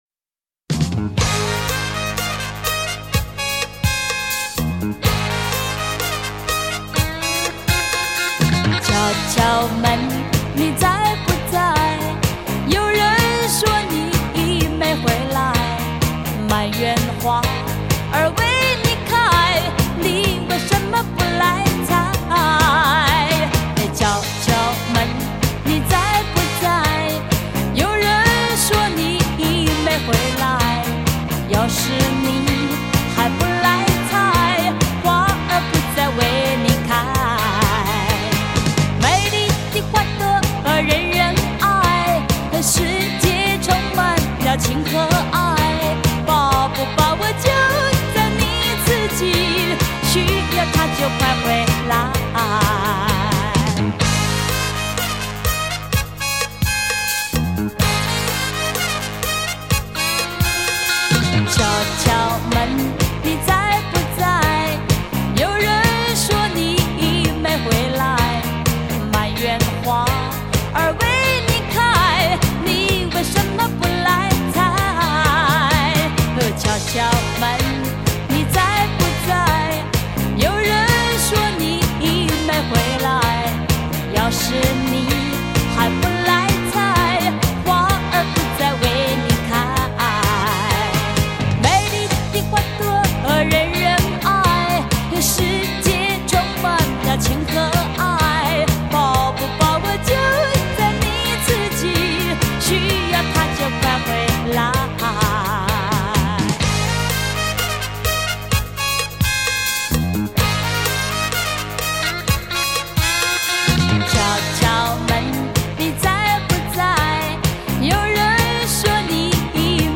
[重新编曲.录音]
本套全部歌曲皆数码系统重新编制 令音场透明度及层次感大为增加 并使杂讯降为最低 在任何音响组合中均可发挥最完美音色